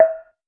btn_Select.wav